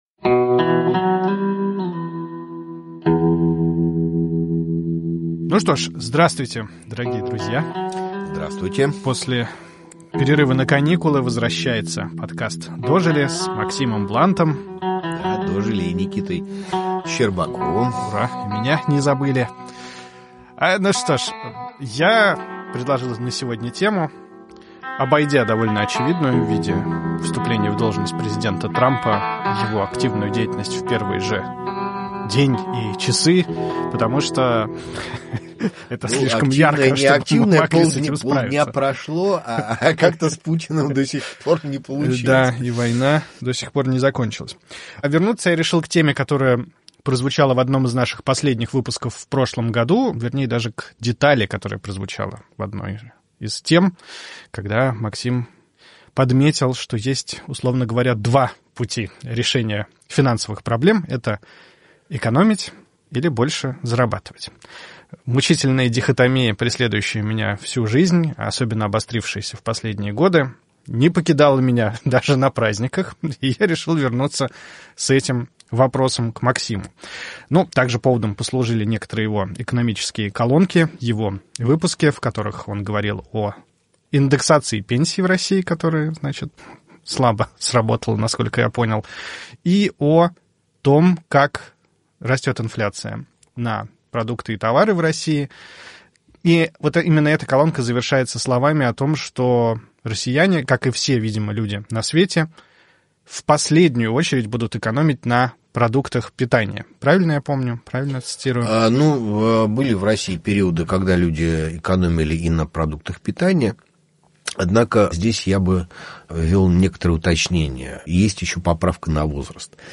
остаются на месте в уютной студии